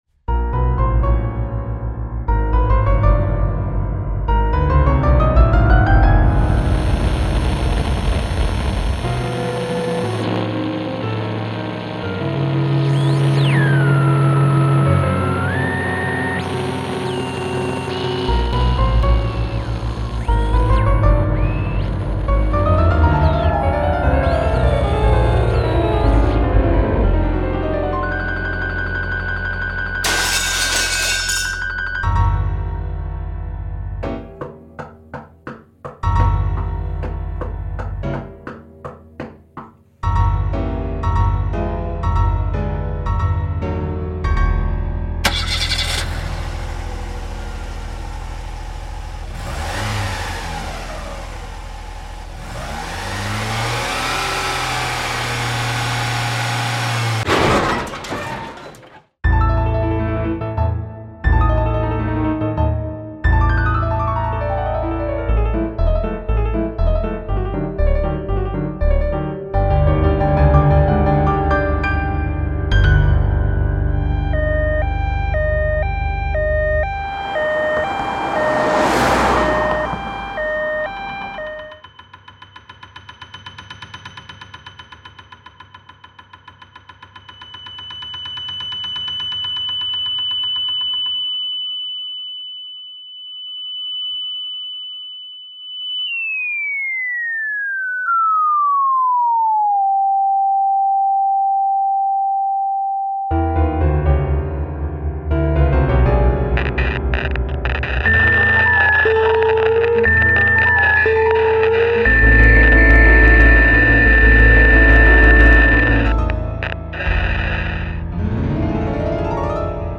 Op5 Nr5 an interesting experiment - Piano Music, Solo Keyboard